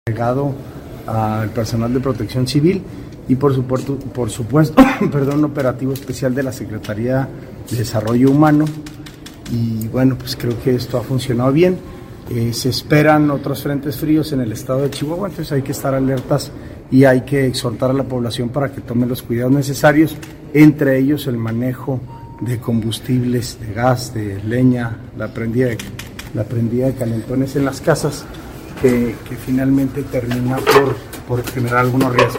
AUDIO: SANTIAGO DE LA PEÑA, TITULAR DE LA SECRETARÍA GENERAL DE GOBIERNO (SGG)